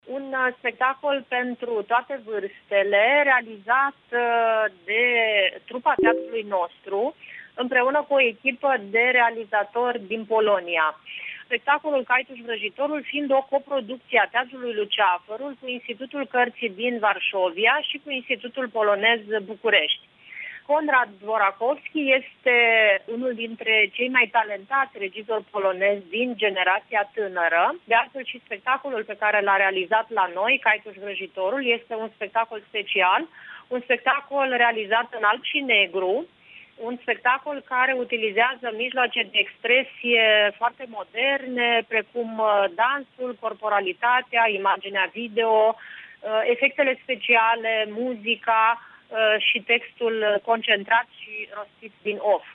Într-o declaraţie acordată postului nostru de radio